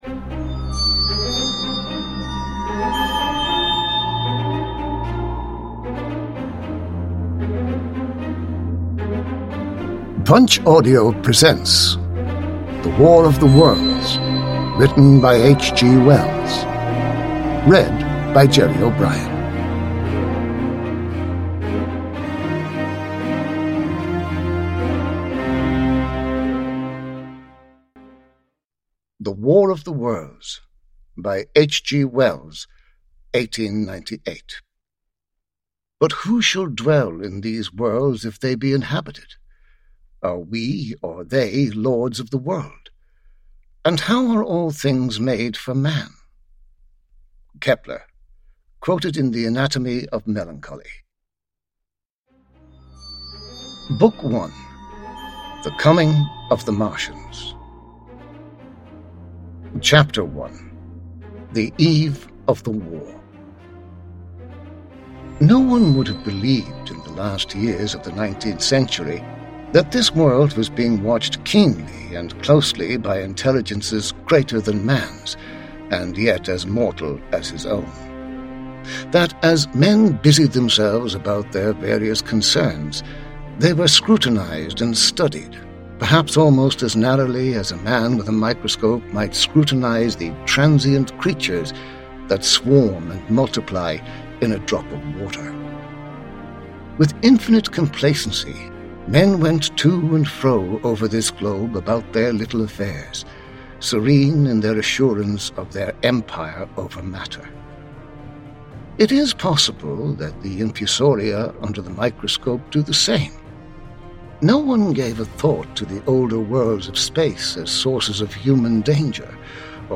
War of the Worlds / Ljudbok